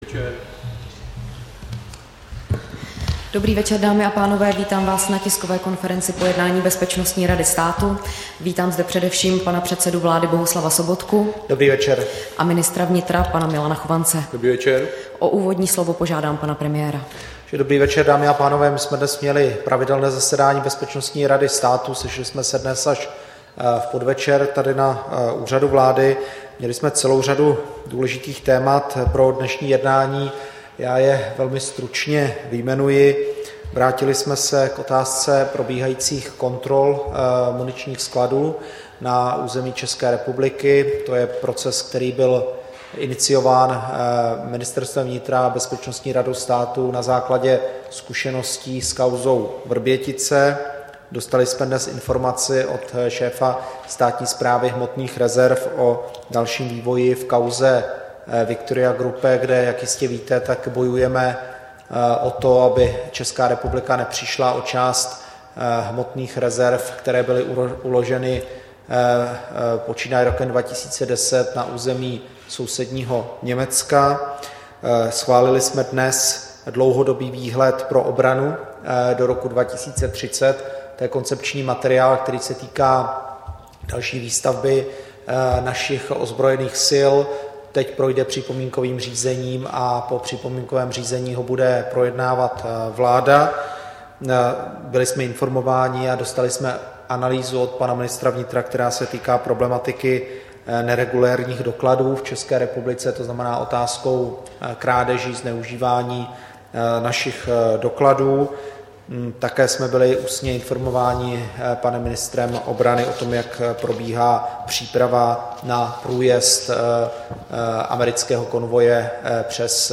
Tisková konference po jednání Bezpečnostní rady státu, 23. března 2015